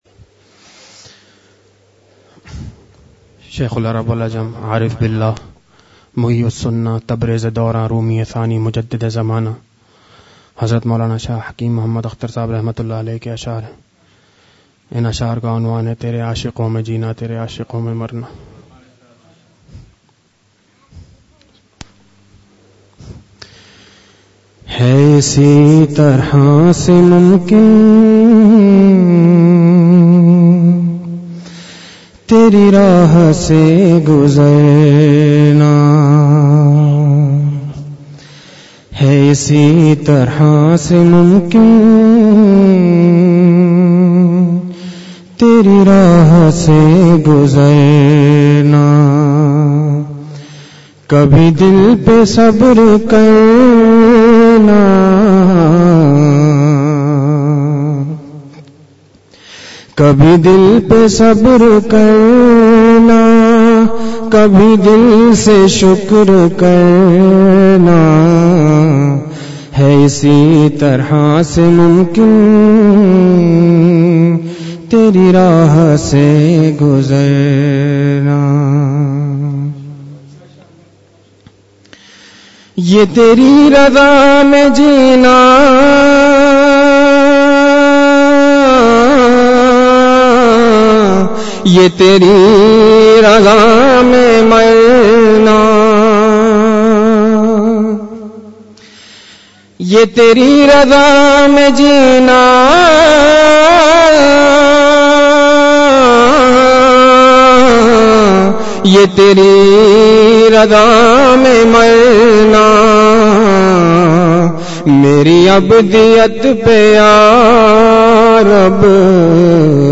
اشعار ِمعرفت پیش فرمائے
کتاب’’آفتاب نسبت مع اللہ ‘‘ سے ملفوظات پڑھ کر سنائے